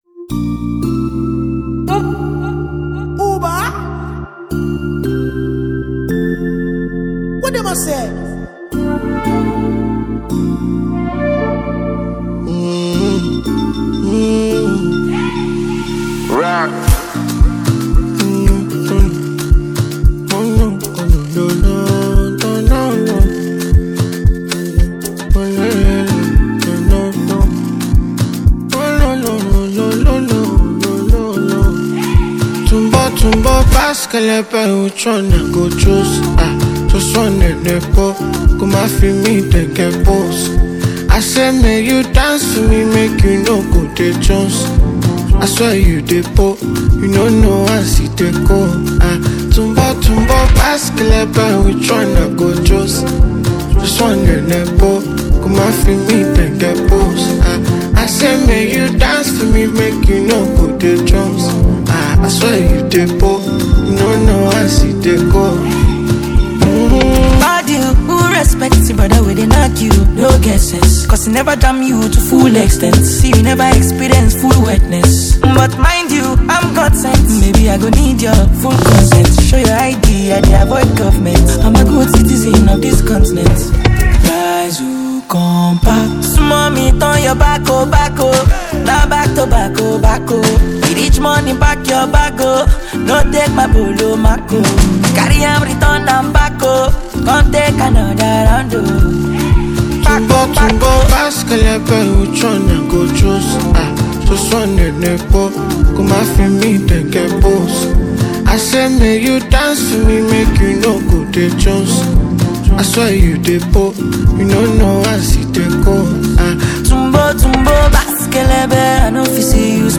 distinctive Afrobeat style
soulful voice and unique musical approach